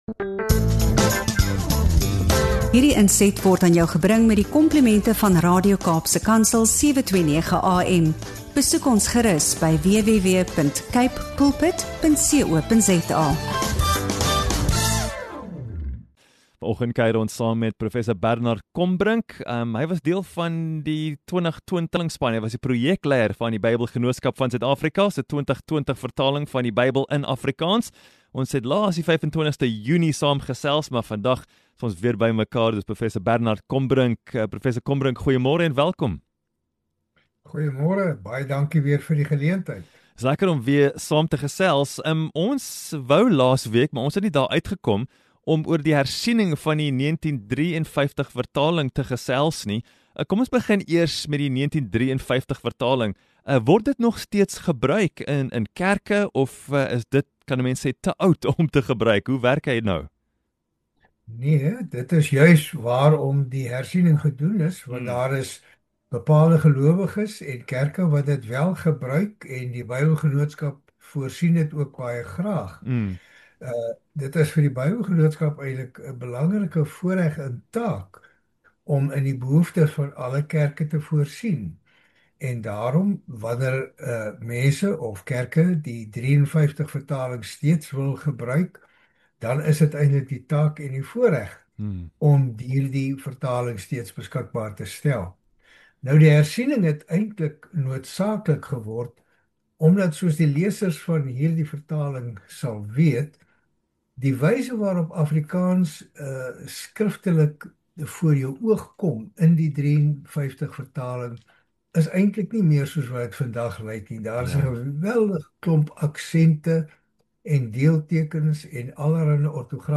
In hierdie insiggewende gesprek